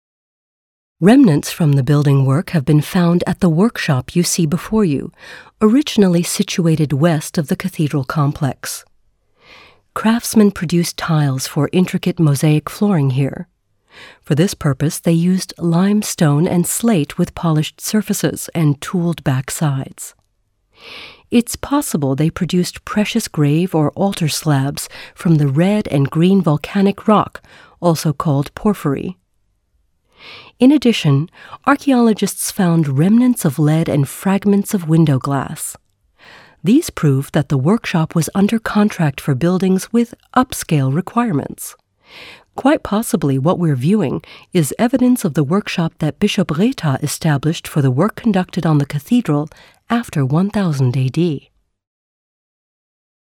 mid-atlantic
Sprechprobe: eLearning (Muttersprache):